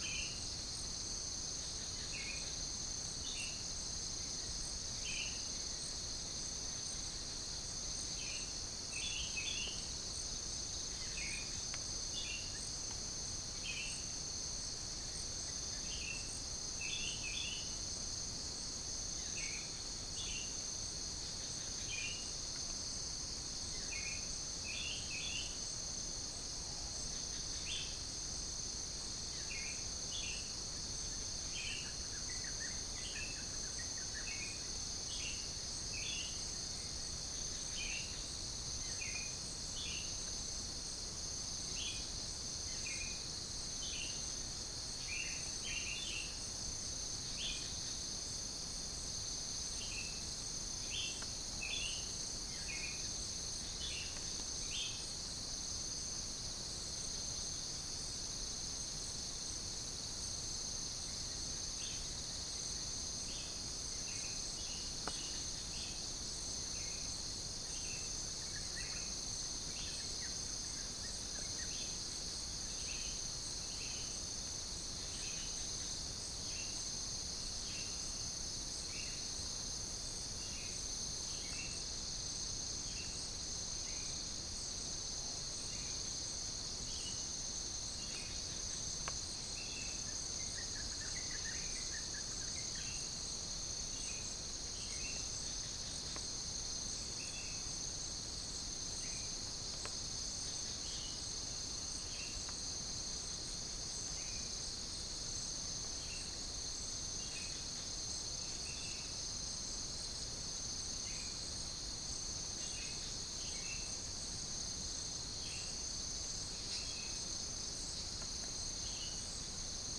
Spilopelia chinensis
Pycnonotus goiavier
Pycnonotus aurigaster
Acridotheres javanicus
Todiramphus chloris